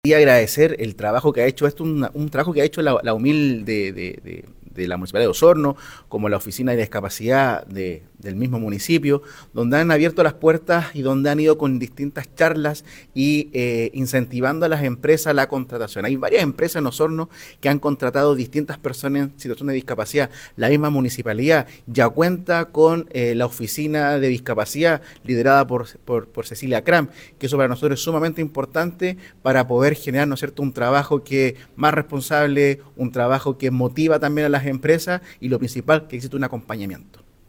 Del mismo modo, el Director de Sence Los Lagos, destacó el trabajo que realiza tanto la Oficina Municipal de Intermediación Laboral y la Oficina de la Discapacidad de Osorno, que han incentivado a las empresas para fomentar a la inclusión en el mundo del trabajo.